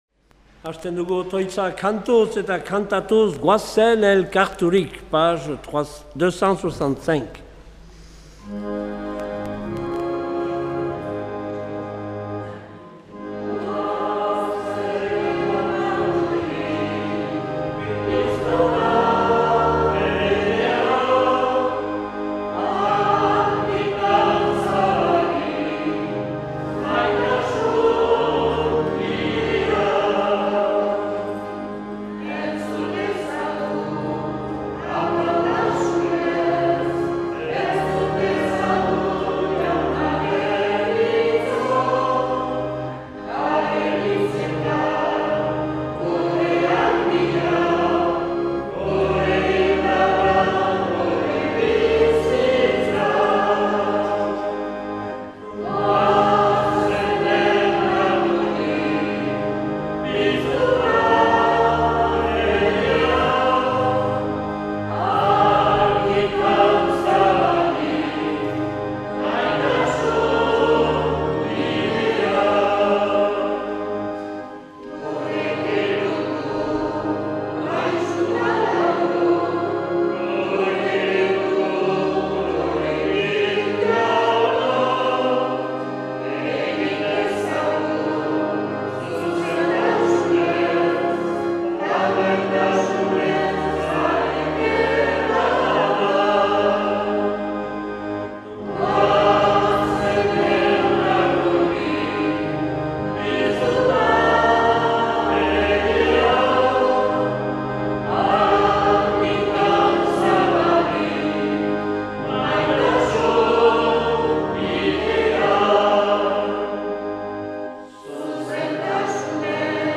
Accueil \ Emissions \ Vie de l’Eglise \ Célébrer \ Igandetako Mezak Euskal irratietan \ 2023-09-24 Urteko 25.